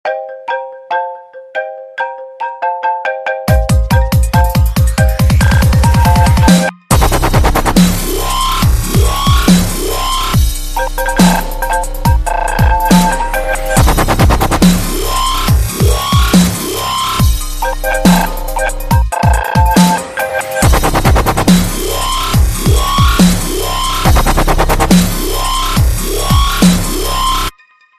• Category: Dubstep (dubstep)
DubStep Remix, version 2449.35 kB128kB6